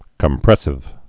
(kəm-prĕsĭv)